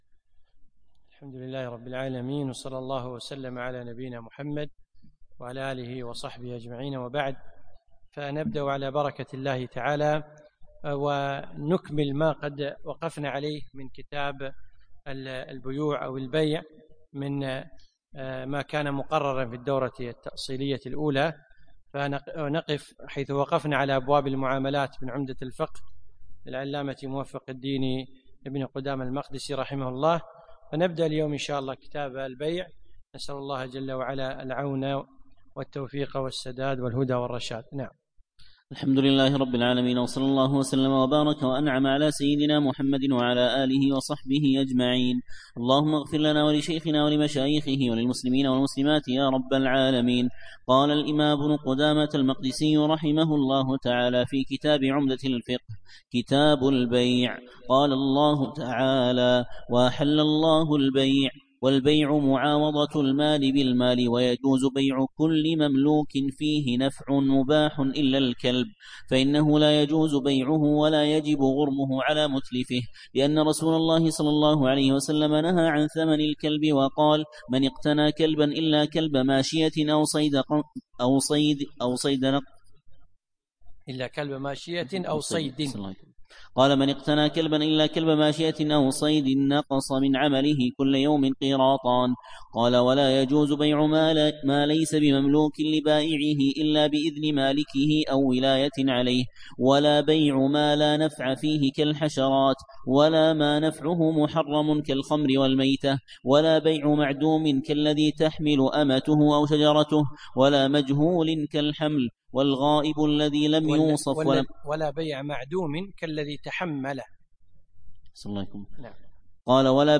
يوم السبت 5 جمادى ثاني 1438 الموافق 4 3 2017 في مسجد عائشة المحري المسايل